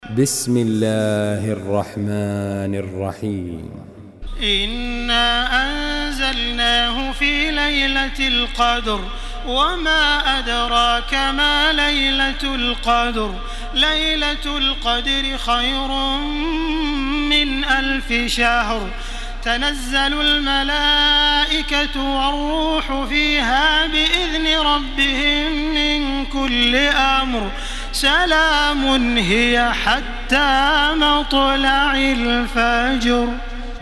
Makkah Taraweeh 1434
Murattal